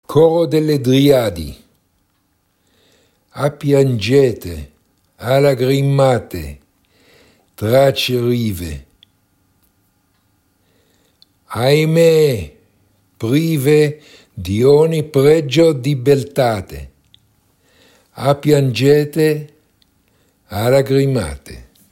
pronunciation
ah_piangete_pron.mp3